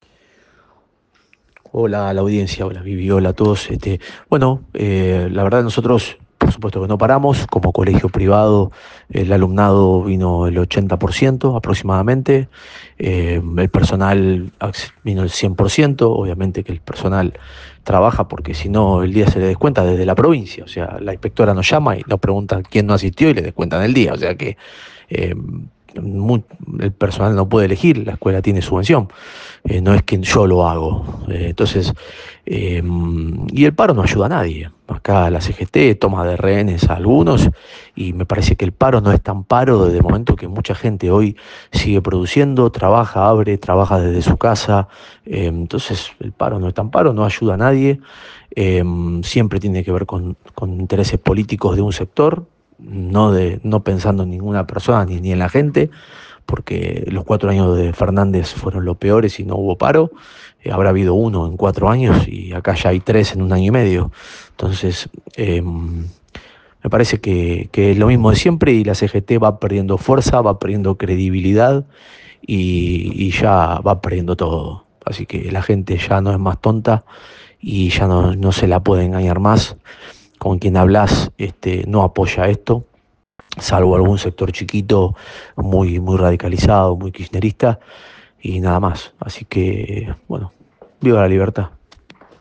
Desde Argentina Política, conversamos con diferentes actores afines al gobierno Nacional.